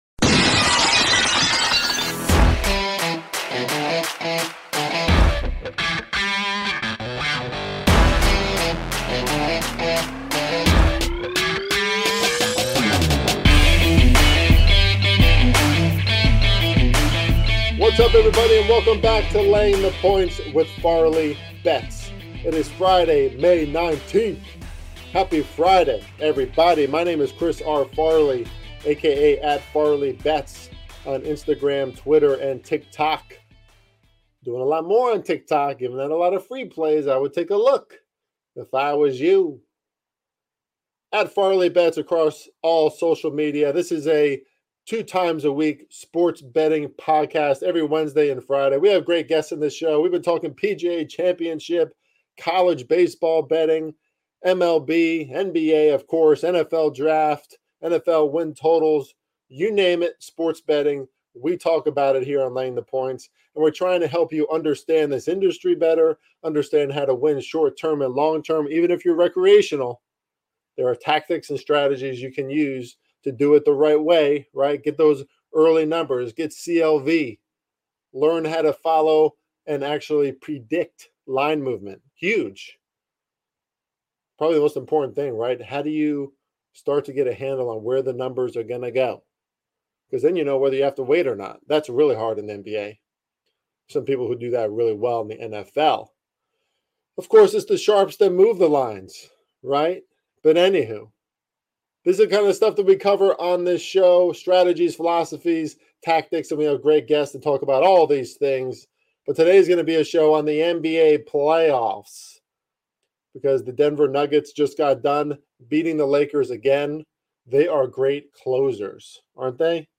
Quite a bit of trash talking on this episode, so put headphones on the kids.